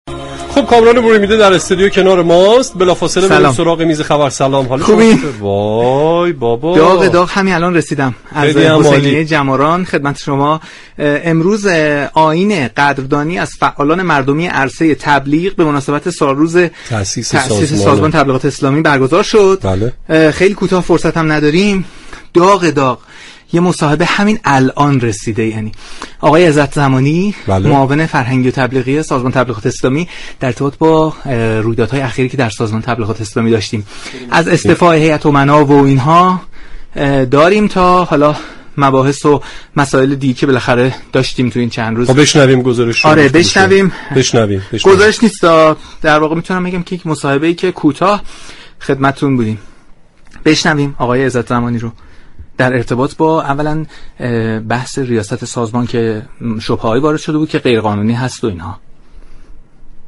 به گزارش پایگاه اطلاع رسانی رادیو قرآن؛ حجت الاسلام رضا عزت زمانی معاون فرهنگی و تبلیغی سازمان تبلیغات اسلامی در گفتگو با برنامه والعصر رادیو قرآن در خصوص بحث منصوب نمودن رئیس دارالقرآن الكریم گفت: اولا مشورت و گفتگو با اعضای هیئت امنا ادامه دارد، اما انتخاب نصب رئیس دارالقرآن الكریم با رئیس سازمان تبلیغات اسلامی است و جزو اختیارات ایشان محسوب می شود كه انشاء الله این امر اتفاقی رو به جلو و مفید برای این مجموعه باشد.